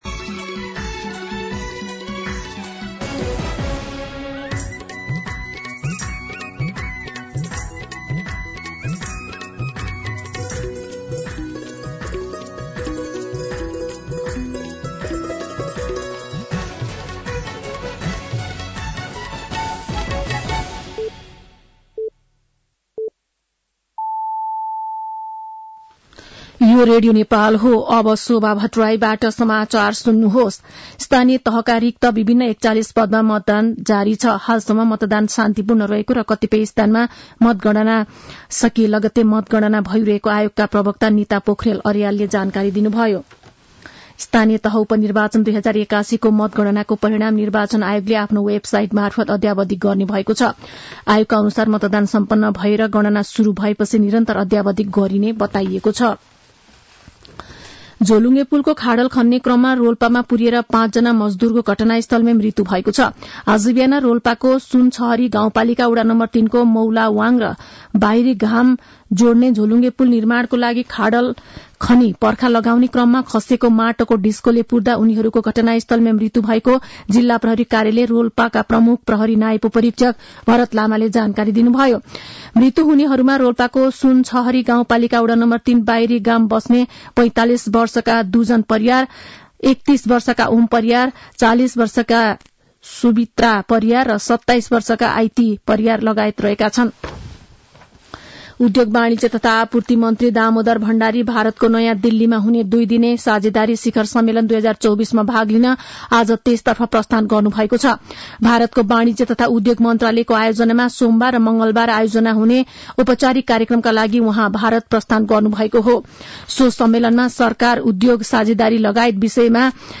साँझ ५ बजेको नेपाली समाचार : १७ मंसिर , २०८१
5-PM-Nepali-News-8-16.mp3